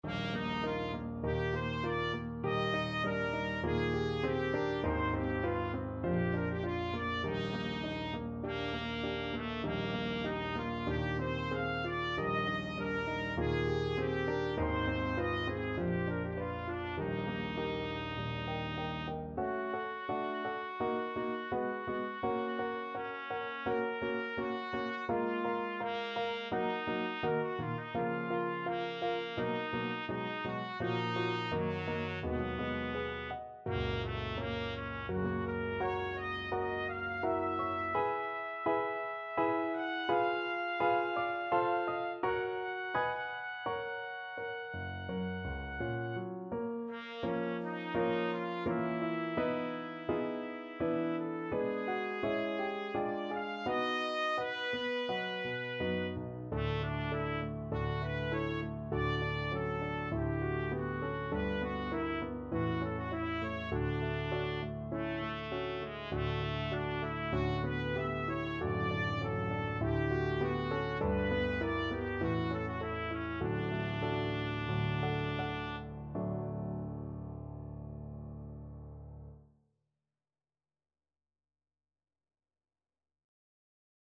4/4 (View more 4/4 Music)
Moderato
F#4-F#6
Classical (View more Classical Trumpet Music)